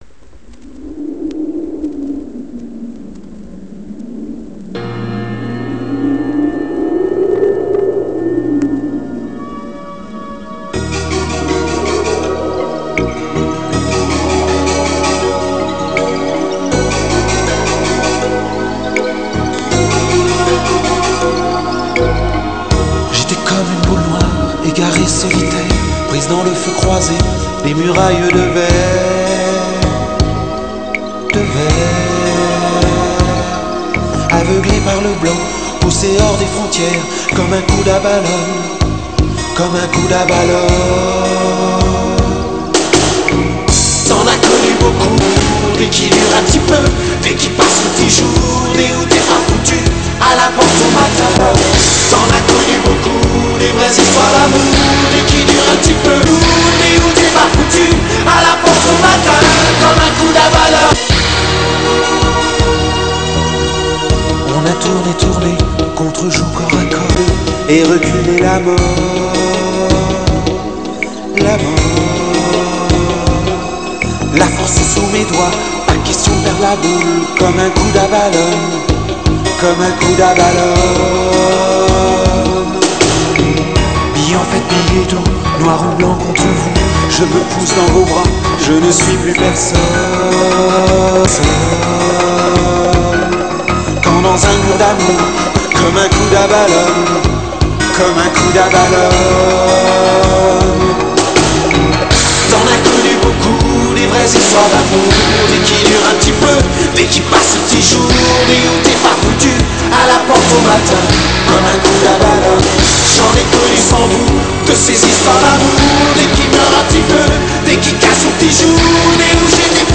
Genere: Pop
Stile: Pianobar alla francese